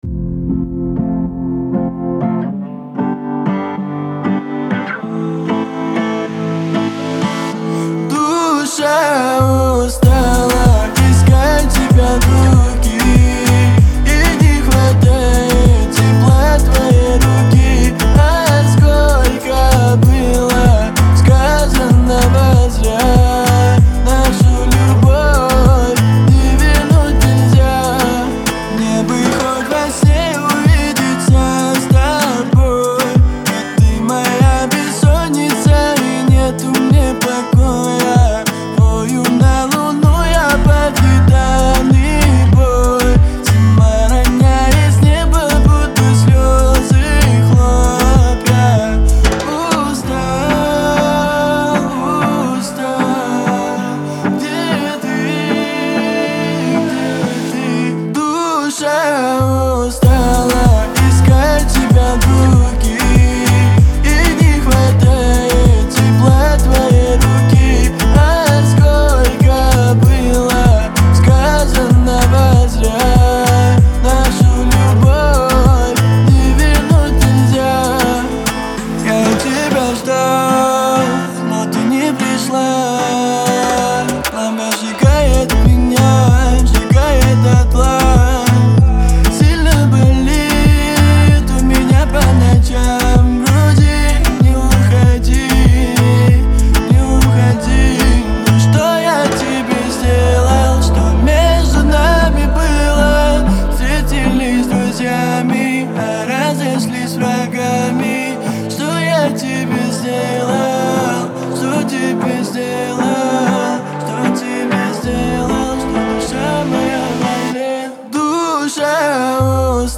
выразительный вокал и атмосферное звучание